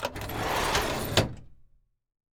DRAWER1 OP-S.WAV